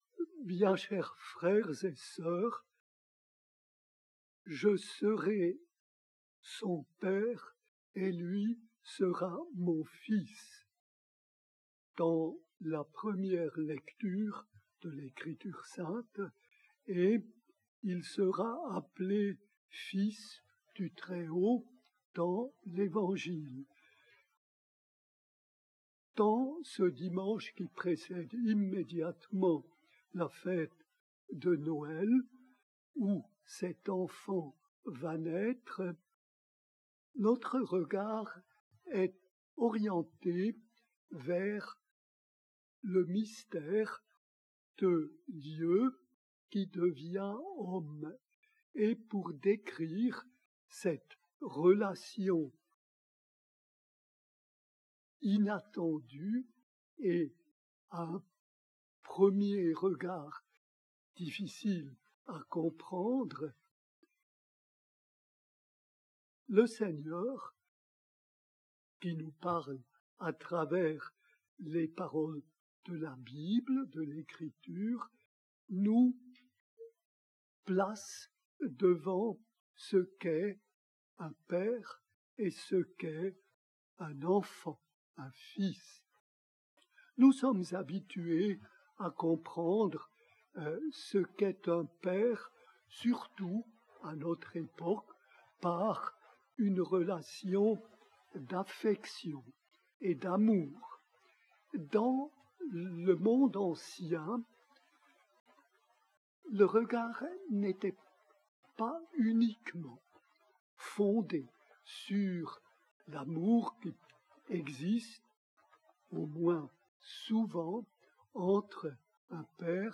Pour découvrir son homllie, nous vous invitons à écouter un enregistrement en direct.